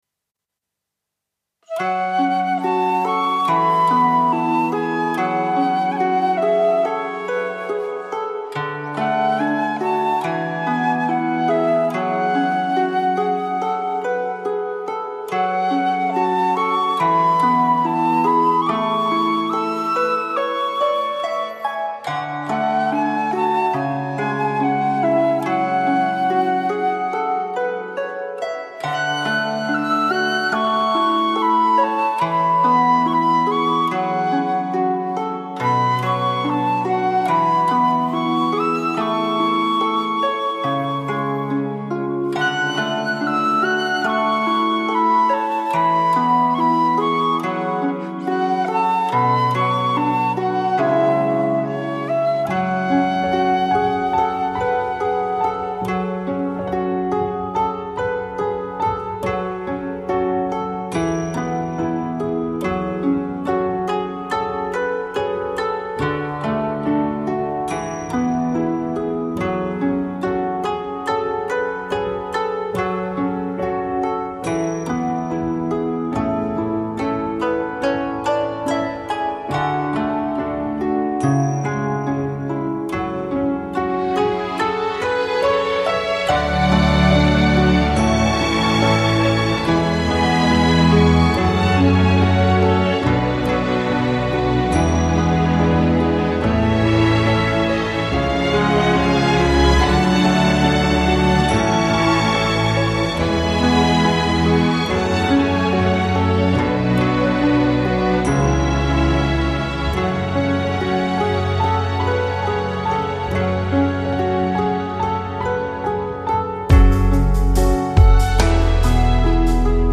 官方原版伴奏